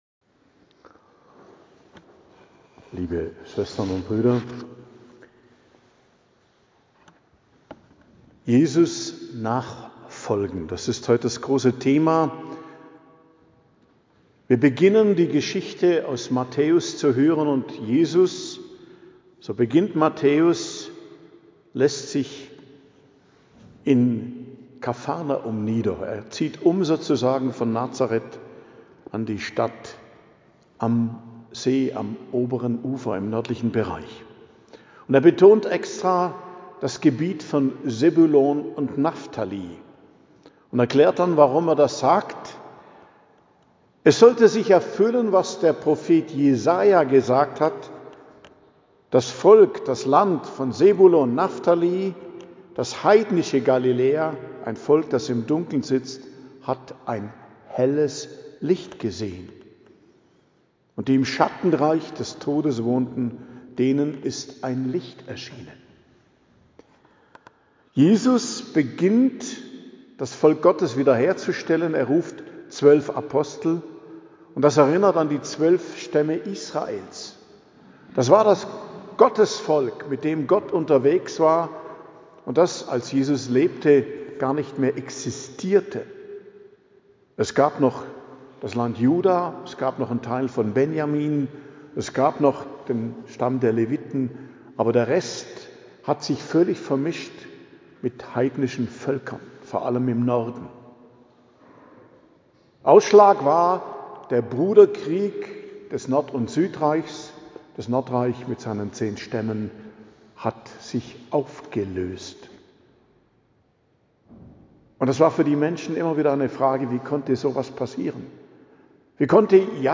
Predigt zum 3. Sonntag i.J., 25.01.2026 ~ Geistliches Zentrum Kloster Heiligkreuztal Podcast